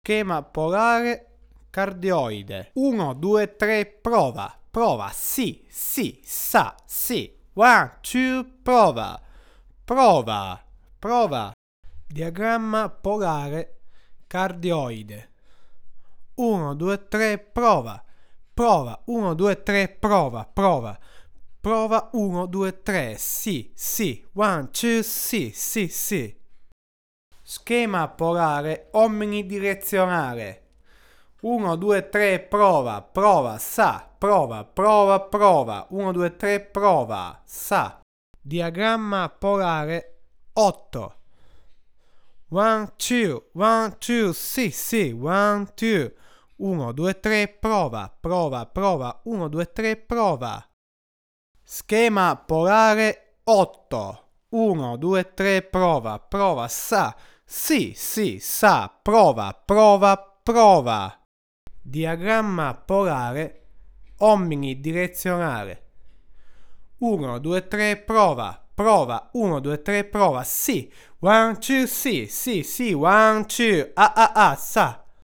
- Final mod, replaced C8 with sub-miniature metalized polyester 1.5uf/400v. This gave me the best balance of clarity, fullness and warmth, which is what "I" wanted from this mic.